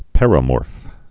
(pĕrə-môrf)